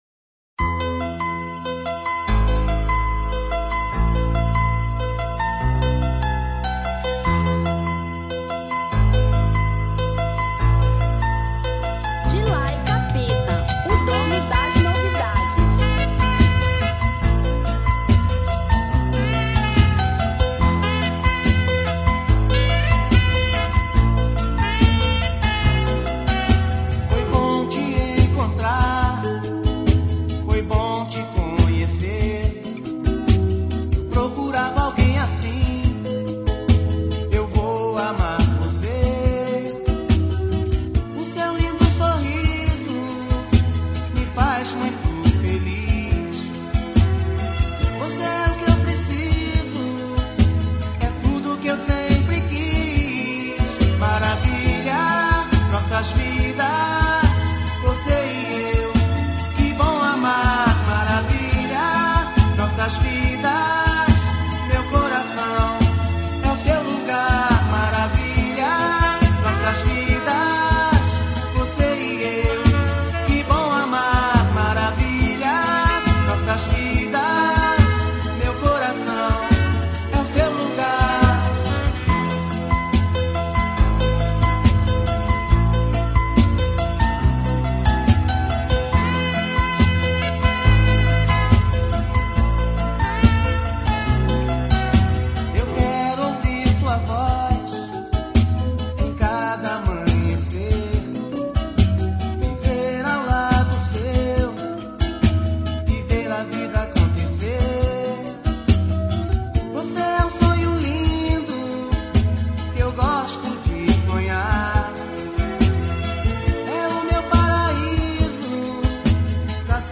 Romântica 1995